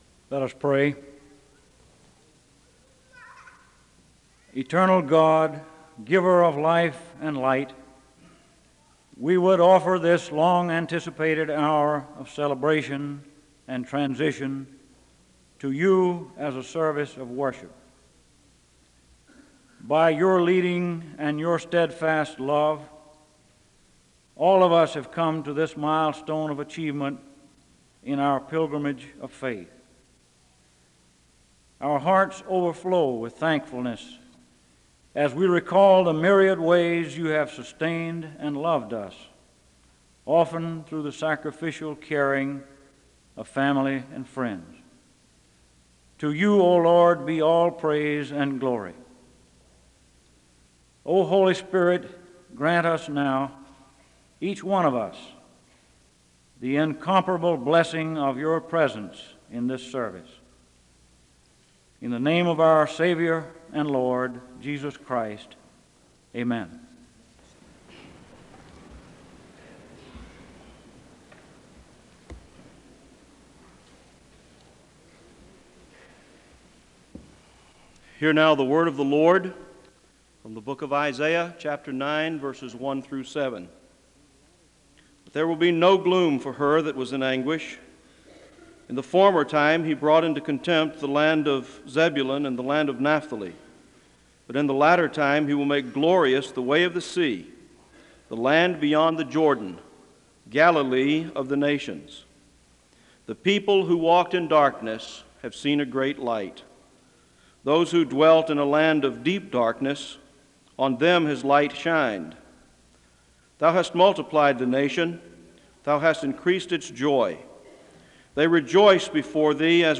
The service begins with a moment of prayer (0:00:00-0:01:14).
There is a song of worship (0:09:21-0:11:31).
The names of the recipients of the Associate of Divinity degree are called so they may come forward and receive their diplomas (0:31:54-0:35:40).
There is a closing prayer (1:01:01-1:02:50).
Commencement ceremonies